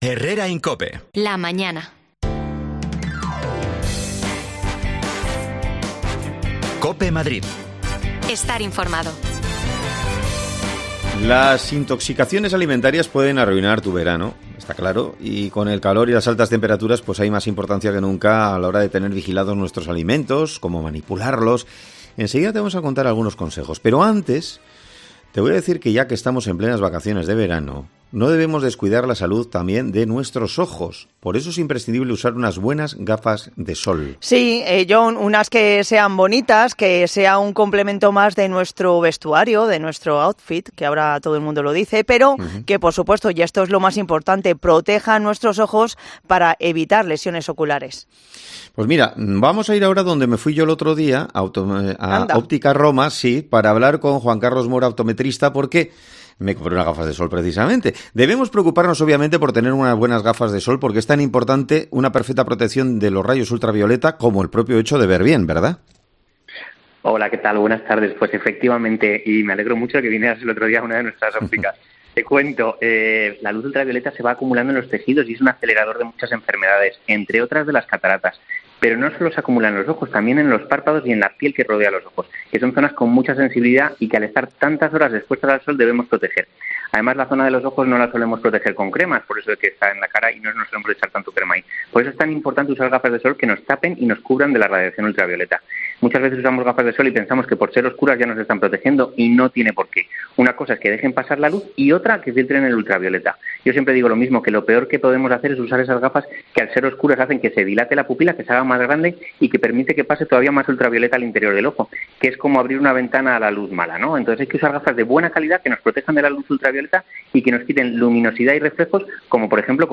Un experto en intoxicaciones alimentarias cuenta y aconseja como cuidar los alimentos este verano para no ingerirlos en mal estado
Las desconexiones locales de Madrid son espacios de 10 minutos de duración que se emiten en COPE, de lunes a viernes.